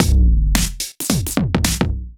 Index of /musicradar/off-the-grid-samples/110bpm
OTG_Kit 1_HeavySwing_110-A.wav